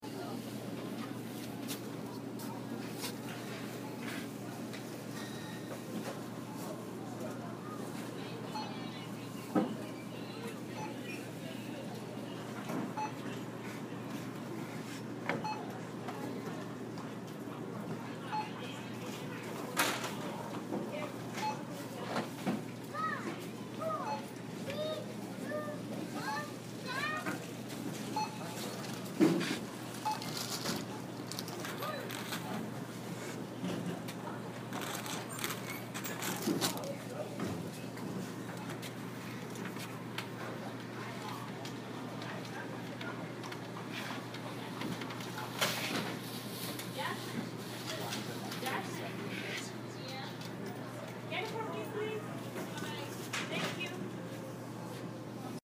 Field Recording #1
Location: Target checkout line, 2/4/15, 9pm
Sounds heard: Mechanical beeping from scanner, footsteps, shuffling, shopping carts rolling by, faint voices, faint voice over a speaker, crumpling plastic
Farthest: child crying, footsteps